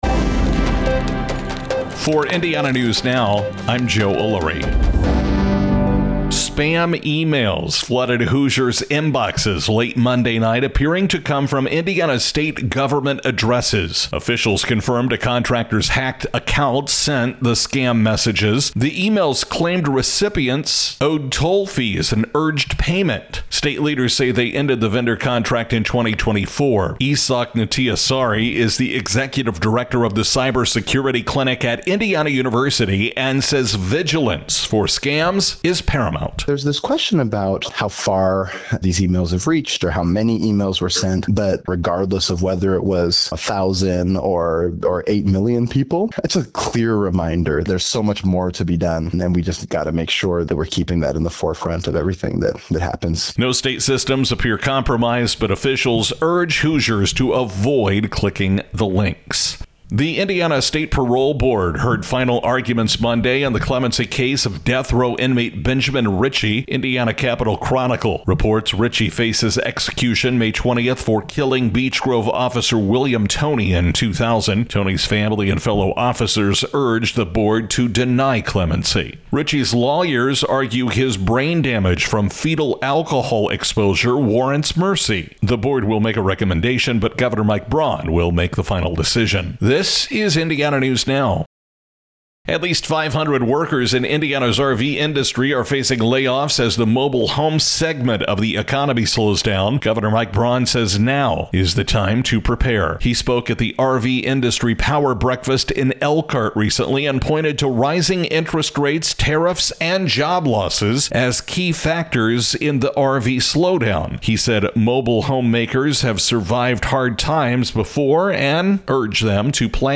Indiana News Now statewide newscast